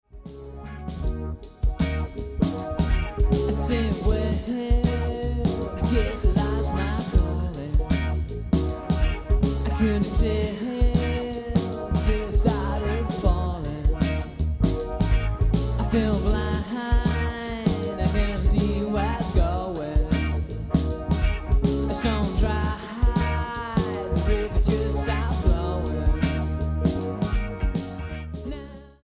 Rock EP